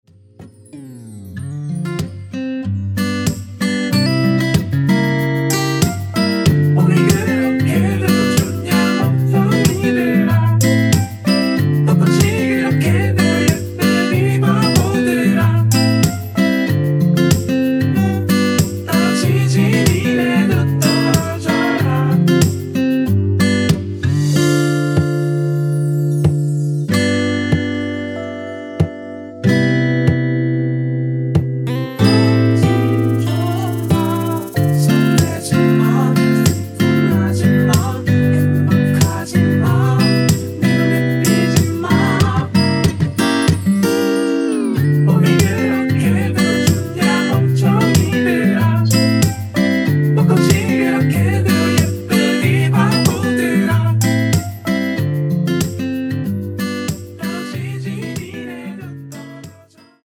원키에서(-1) 내린 코러스 포함된 MR 입니다.(미리듣기 참조)
앞부분30초, 뒷부분30초씩 편집해서 올려 드리고 있습니다.
중간에 음이 끈어지고 다시 나오는 이유는